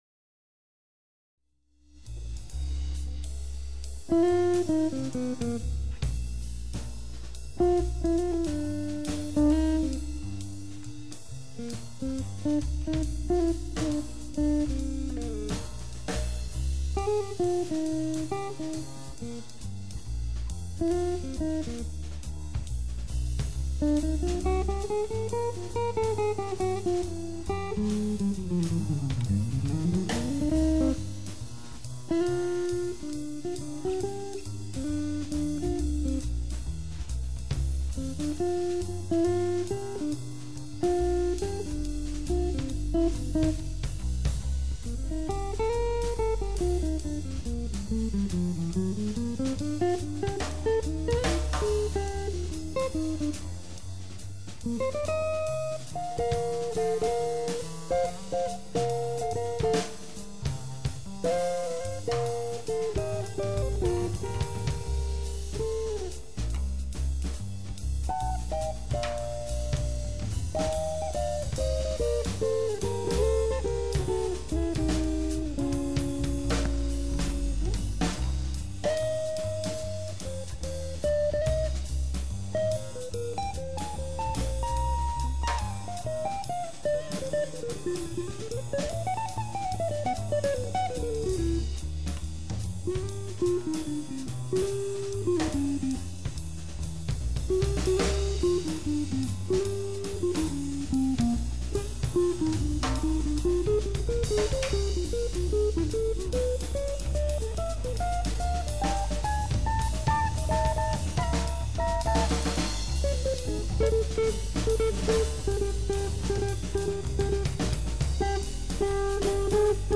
acoustic jazz quartet